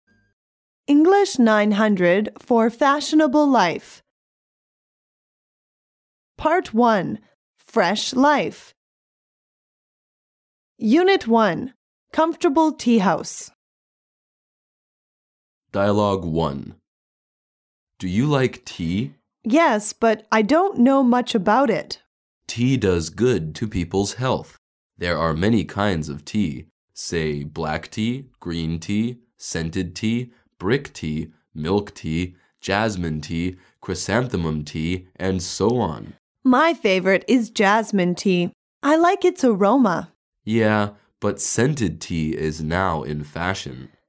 Dialouge 1